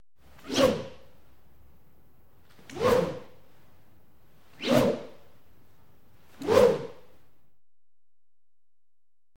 На этой странице собраны звуки копья: удары, скрежет, броски и другие эффекты.
Шум взмаха копьем